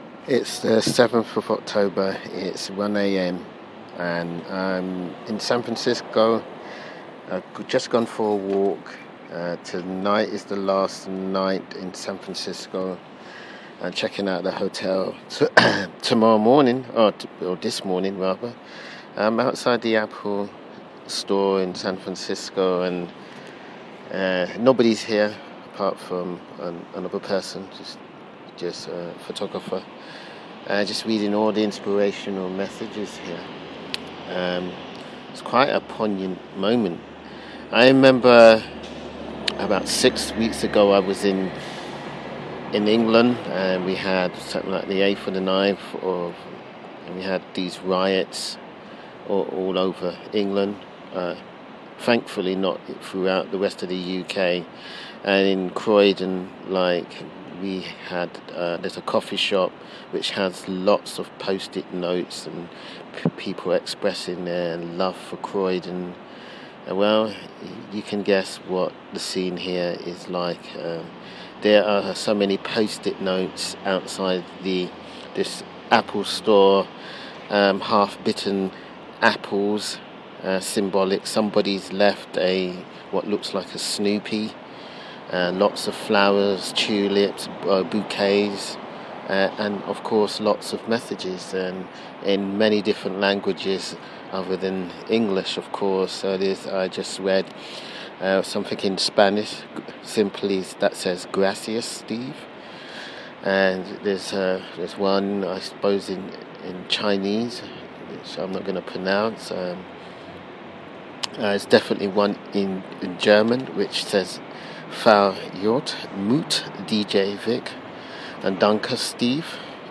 Outside The Apple Store San Francisco at 1am